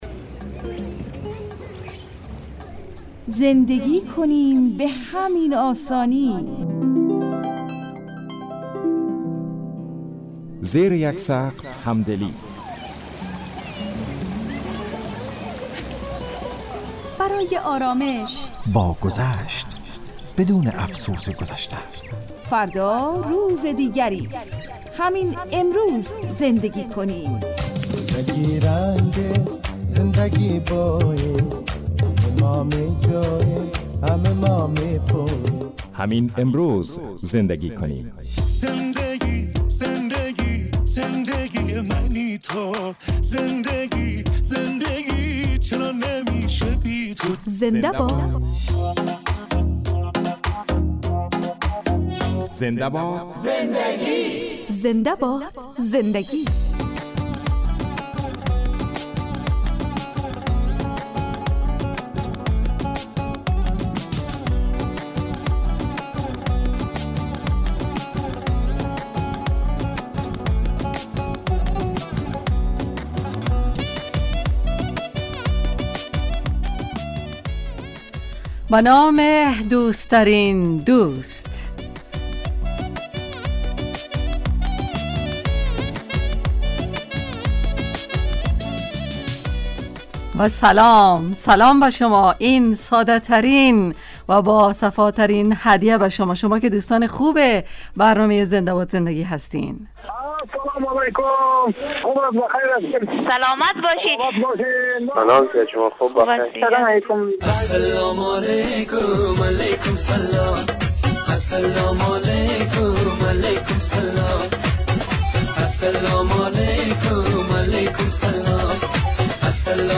برنامه خانوادگی رادیو دری